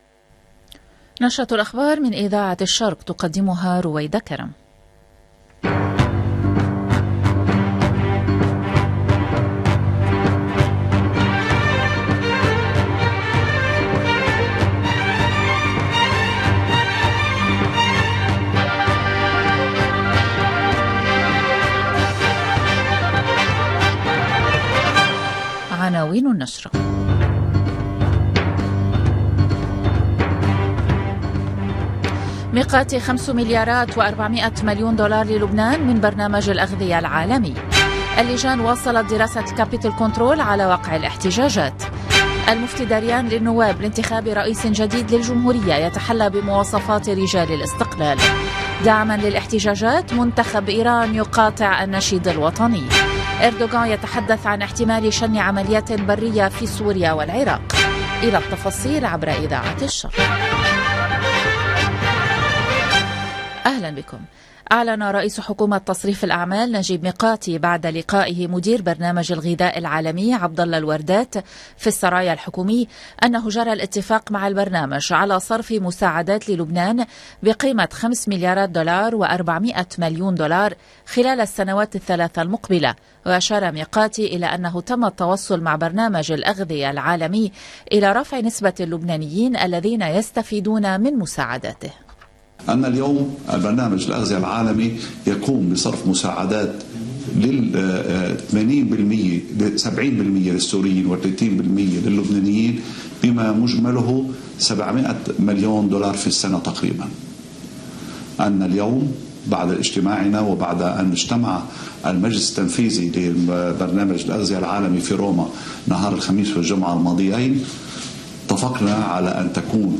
LE JOURNAL DU LIBAN DU SOIR DU 21/11/2022
EDITION DU JOURNAL DU SOIR DU LIBAN EN LANGUE ARABE DU 21/11/2022